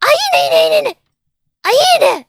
Worms speechbanks
Collect.wav